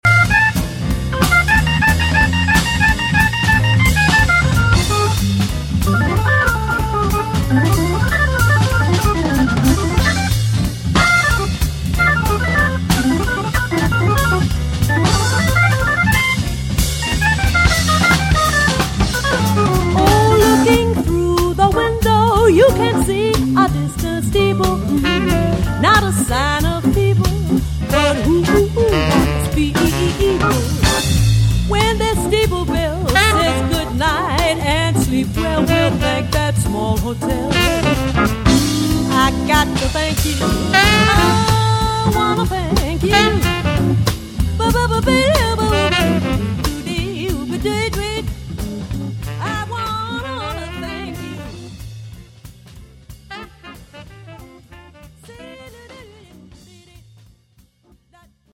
On commence dans le solo de l'orgue.